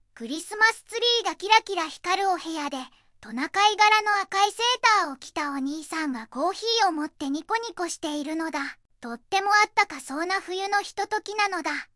(こんな感じのセリフです Voicevoxで作成)